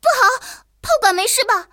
野牛小破语音2.OGG